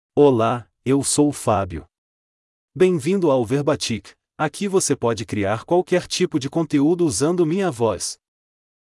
Fabio — Male Portuguese (Brazil) AI Voice | TTS, Voice Cloning & Video | Verbatik AI
Fabio is a male AI voice for Portuguese (Brazil).
Voice sample
Male
Fabio delivers clear pronunciation with authentic Brazil Portuguese intonation, making your content sound professionally produced.